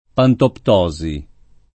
DOP: Dizionario di Ortografia e Pronunzia della lingua italiana
[ pantopt 0@ i ]